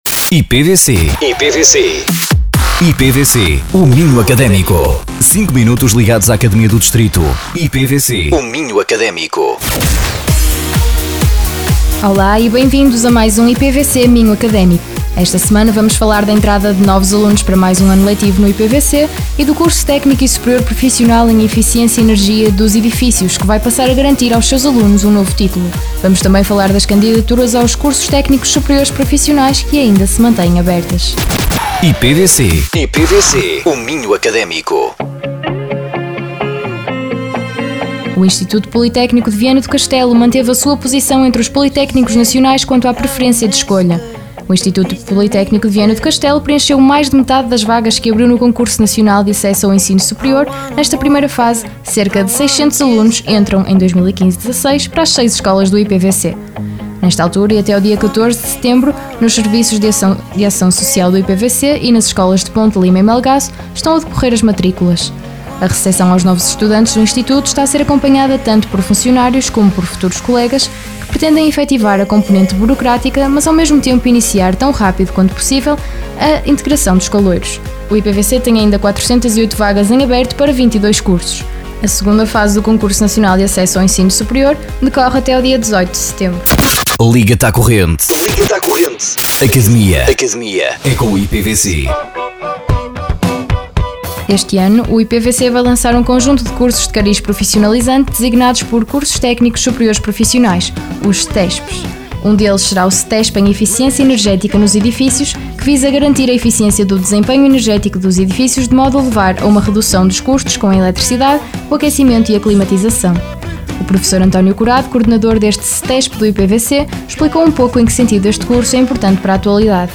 O programa é transmitido todas as quartas-feiras às 11h00, 13h00 e 17h00 e aos domingos às 14h00 e às 20h00.
Entrevistados: